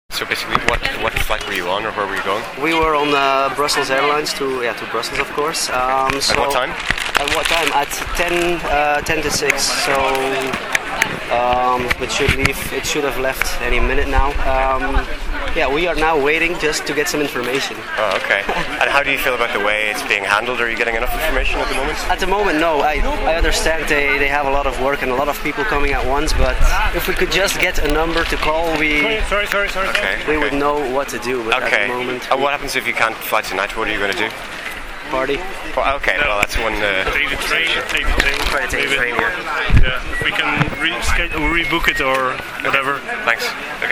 A Belgian passenger who says he'll party if he can't fly tonight.